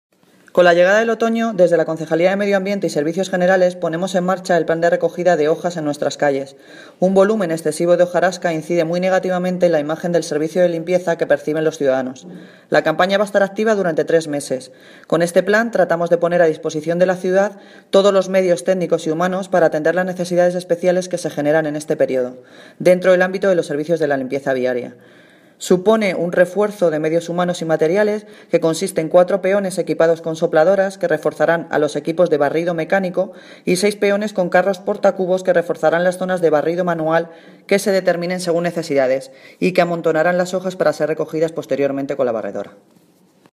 Audio - Noelia Posse (Concejala de Medio Ambiente y Servicios Generales) Sobre Retirada de Hojas